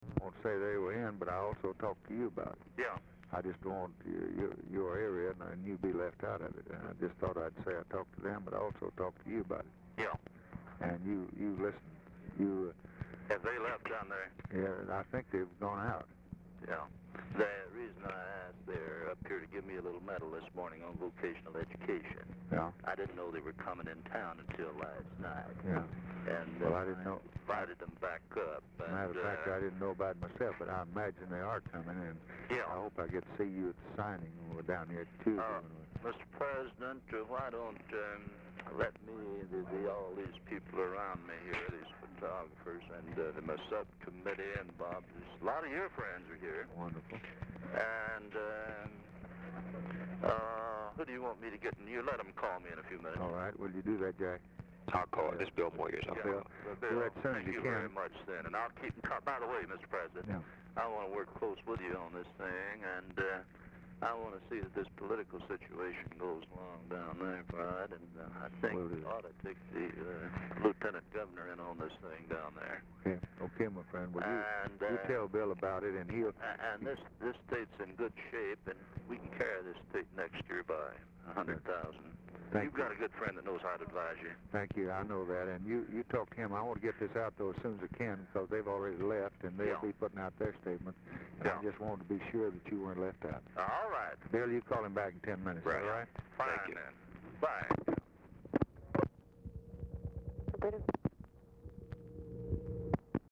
Telephone conversation # 463, sound recording, LBJ and CARL PERKINS, 12/13/1963, 11:10AM | Discover LBJ
Format Dictation belt
Location Of Speaker 1 Oval Office or unknown location
Specific Item Type Telephone conversation Subject Congressional Relations Education Elections Legislation National Politics Press Relations